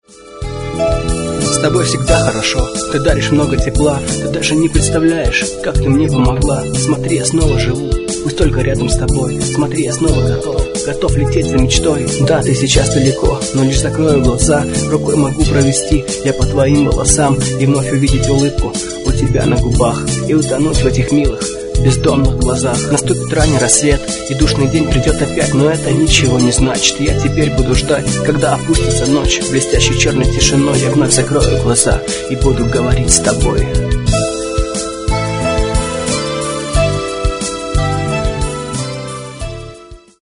Клавиши, перкуссия, вокал
фрагмент (236 k) - mono, 48 kbps, 44 kHz